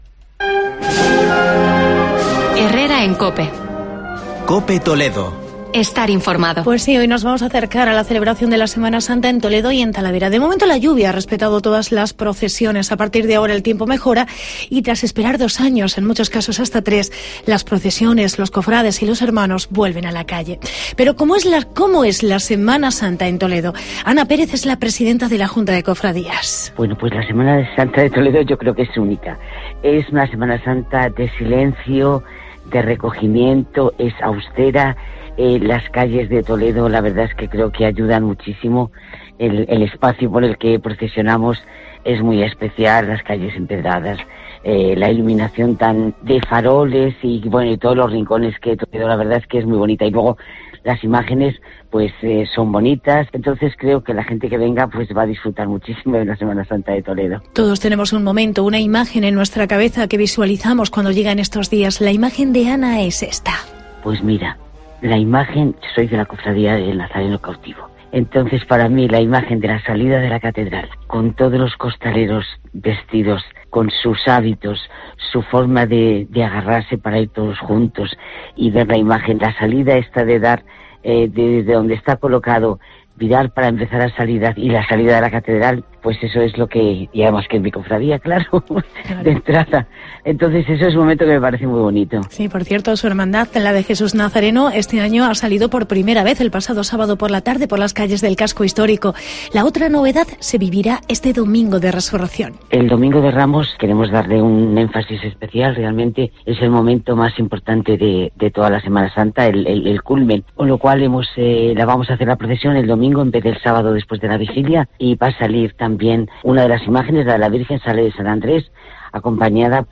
Reportaje Semana Santa en Toledo y Talavera de la Reina